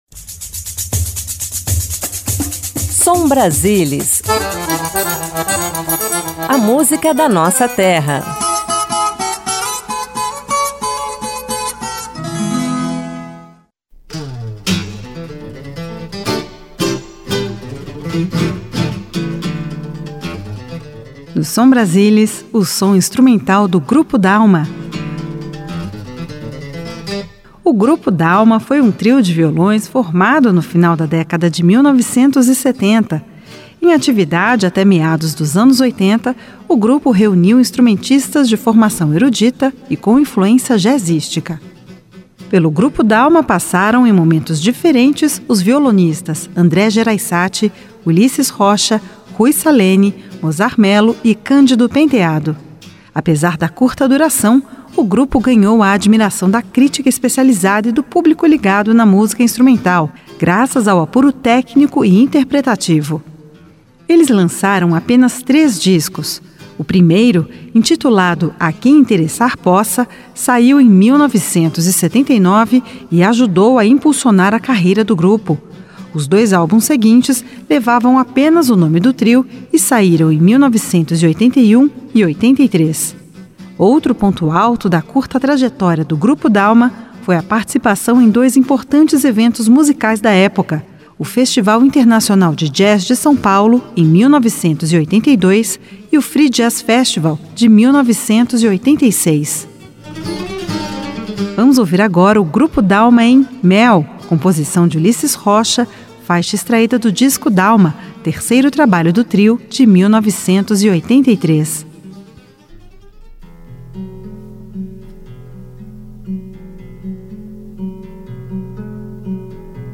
Música Brasileira Violão clássico Jazz Música instrumental